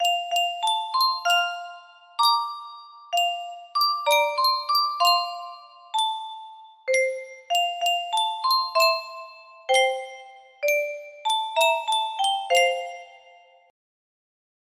Yunsheng Music Box - On Top of Old Smokey 6342 music box melody
Full range 60